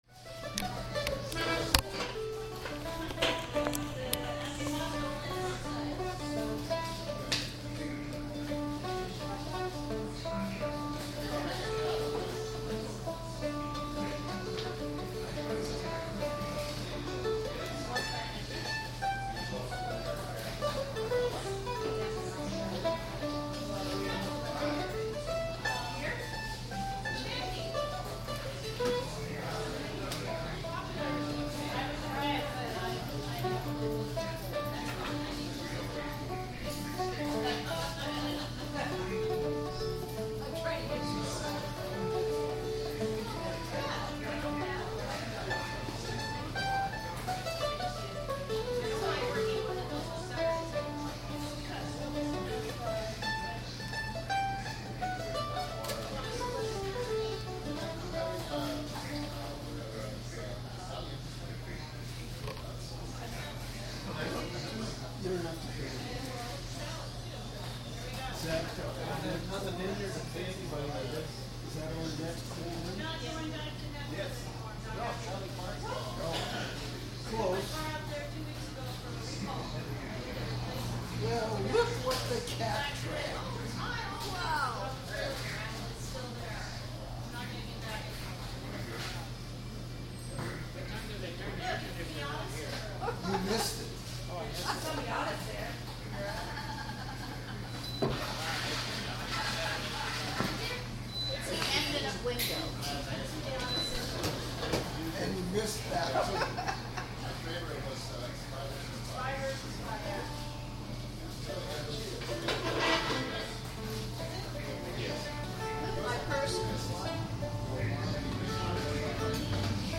big powwow [D]